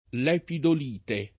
lepidolite [ l H pidol & te ] s. f. (min.)